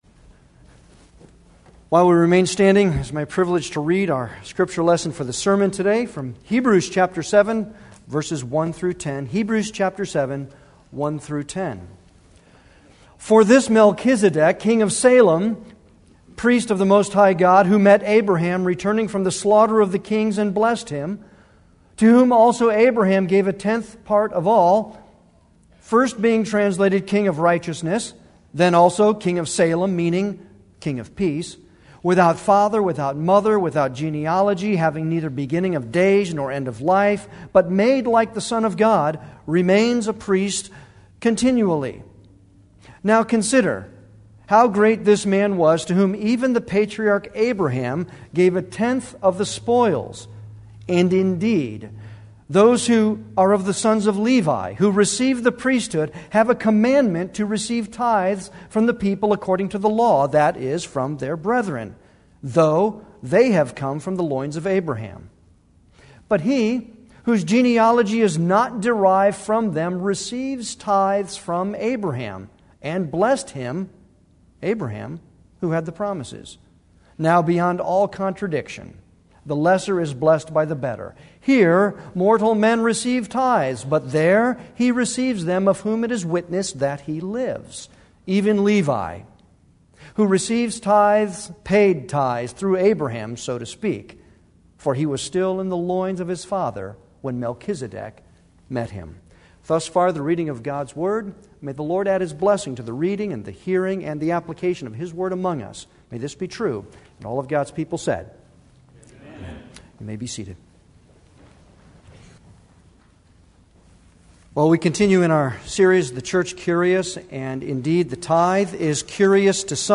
Passage: Hebrews 7:1-10 Service Type: Sunday worship